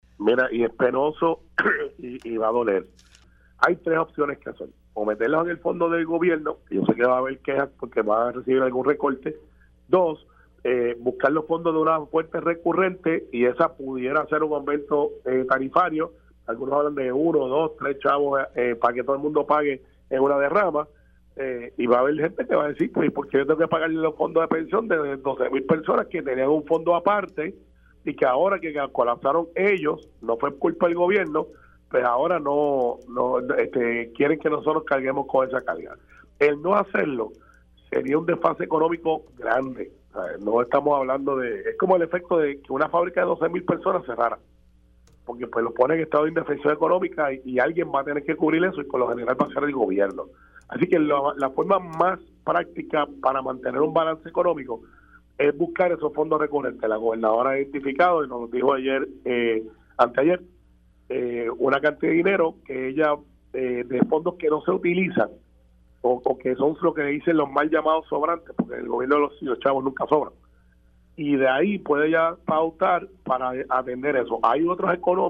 El vicepresidente del Senado, Carmelo Ríos indicó en Pega’os en la Mañana que una opción para resolver la crisis de las pensiones de los jubilados de la Autoridad de Energía Eléctrica (AEE) podría ser crear un fondo de inversión con dinero de la reserva.
405-CARMELO-RIOS-VICEPRESIDENTE-SENADO-HAY-3-OPCIONES-PARA-ALVAR-PENSIONES-DE-LA-AEE.mp3